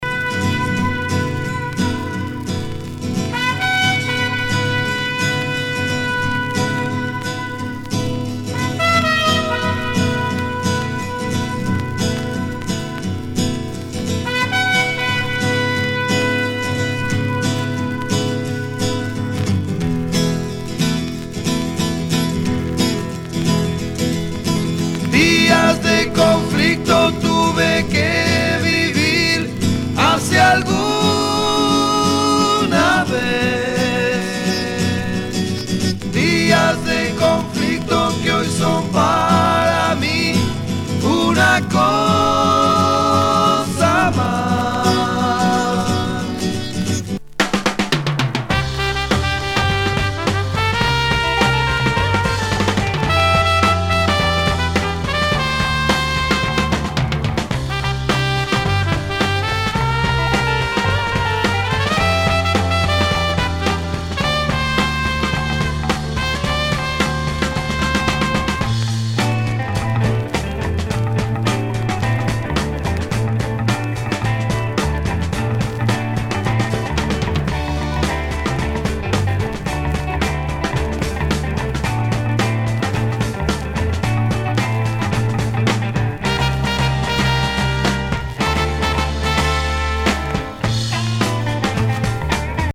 ロマンチック・メロディが癖になる南米フォーキー
サイケ・ジャズ・ロック